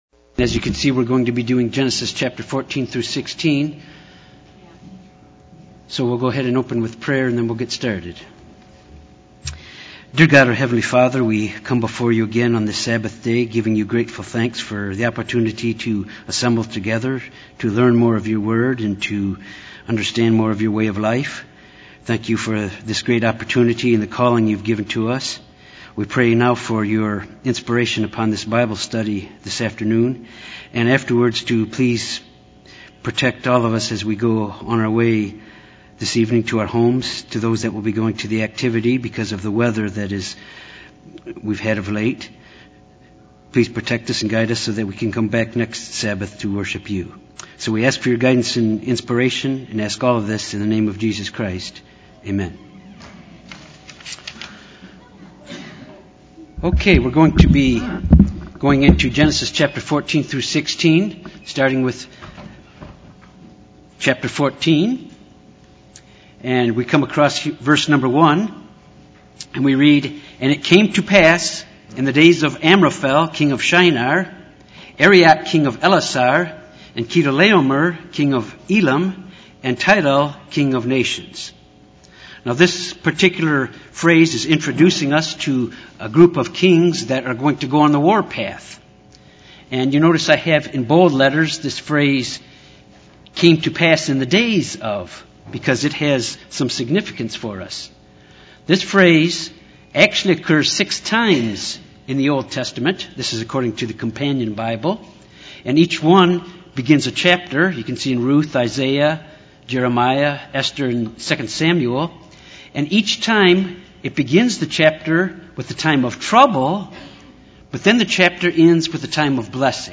This Bible study focuses on Genesis 14-16.